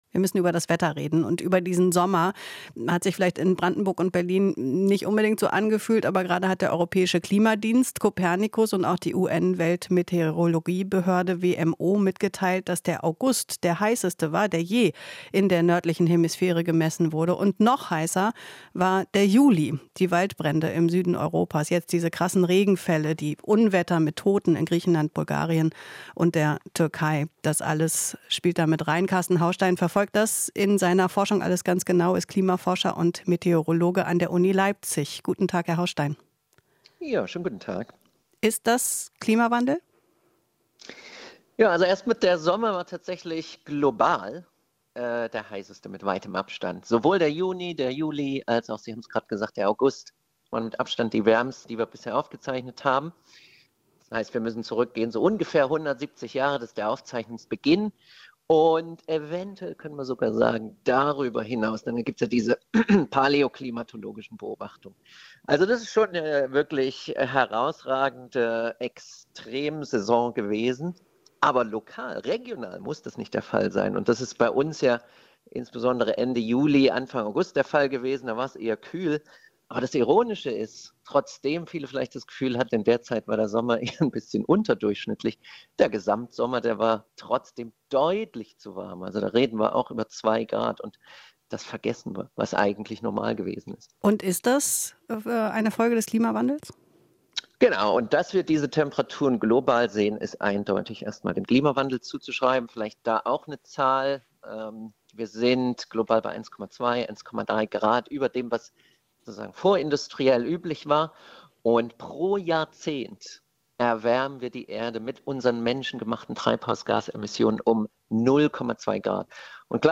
Interview - Klimaforscher: 2023 der mit Abstand wärmste Sommer seit Aufzeichnungsbeginn